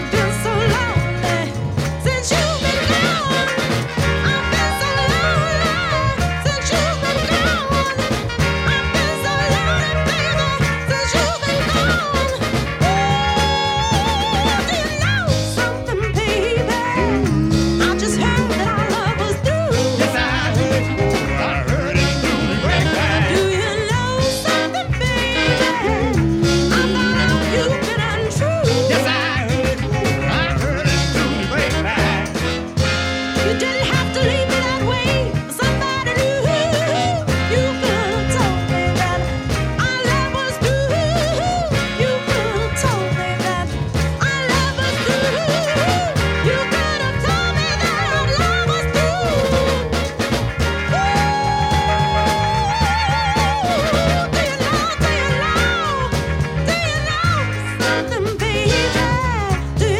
soulful dancers
Her powerful, gospel inflected perfromance on the rare gem A